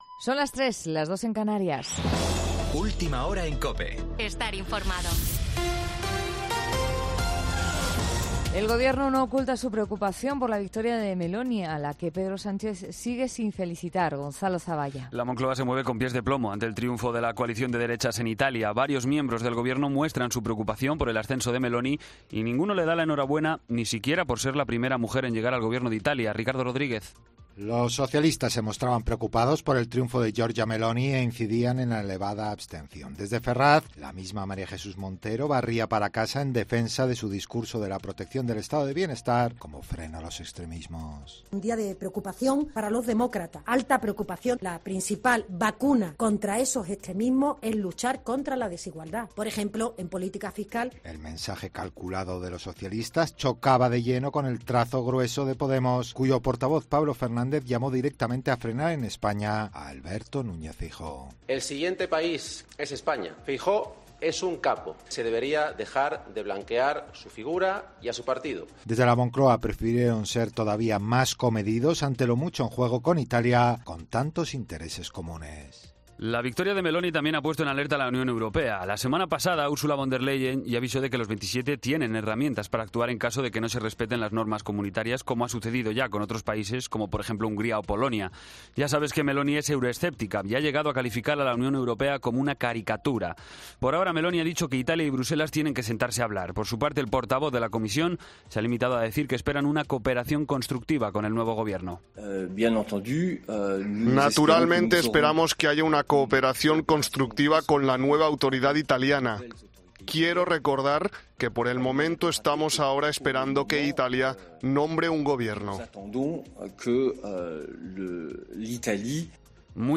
Boletín de noticias COPE del 27 de septiembre a las 03:00 hora